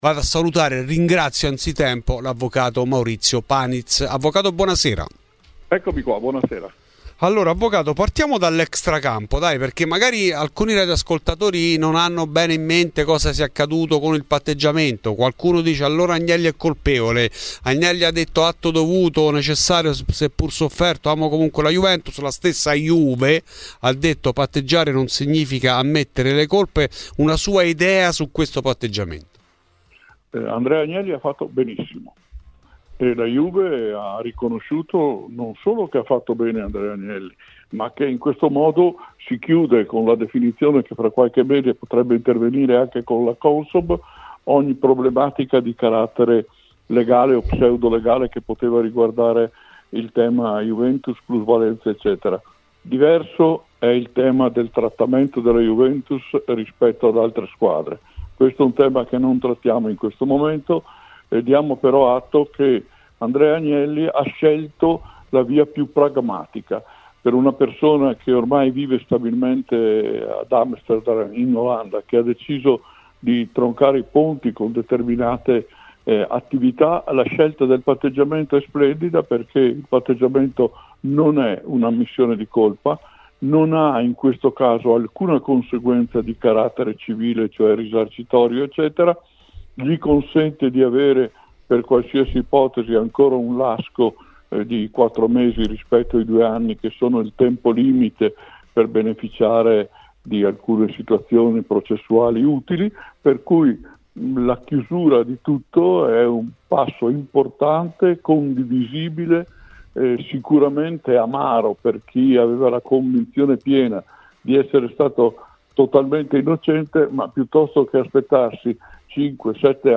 A Fuori di Juve , trasmissione di Radio Bianconera , è intervenuto l'avvocato Maurizio Paniz , noto tifoso bianconero.